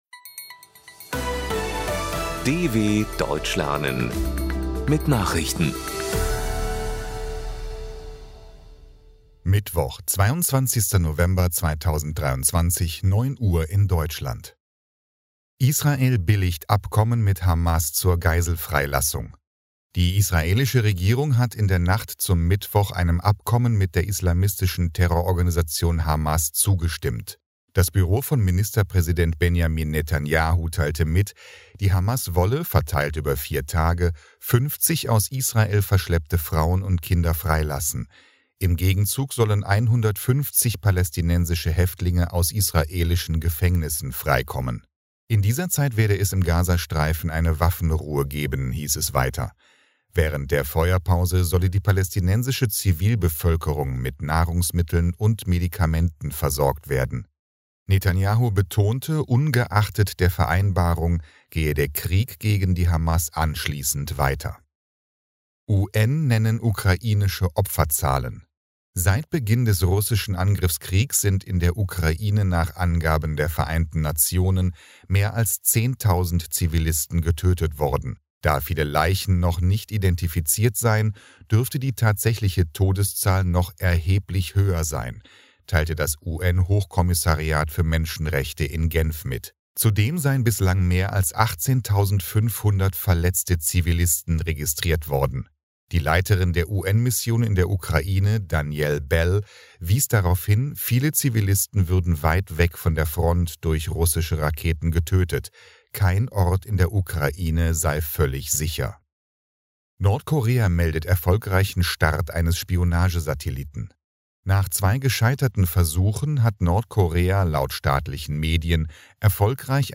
22.11.2023 – Langsam Gesprochene Nachrichten
Trainiere dein Hörverstehen mit den Nachrichten der Deutschen Welle von Mittwoch – als Text und als verständlich gesprochene Audio-Datei.